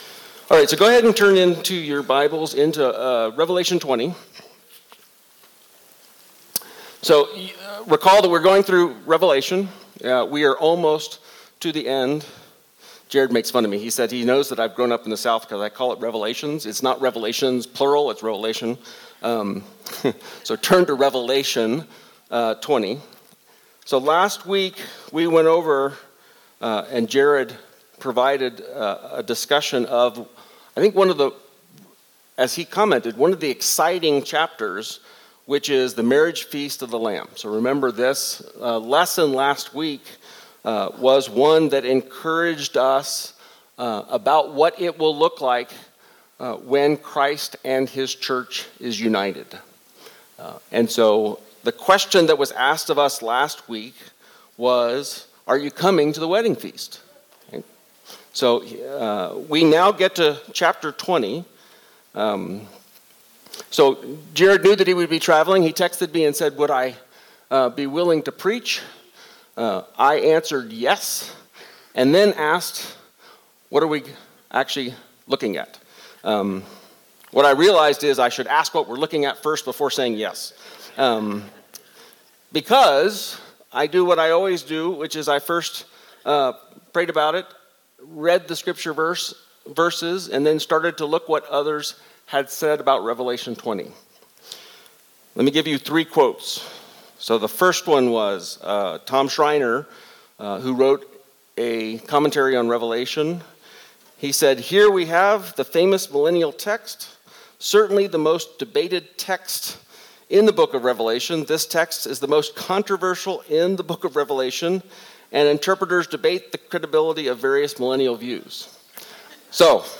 Worship Listen Sermon Join us as we hear from one of our elders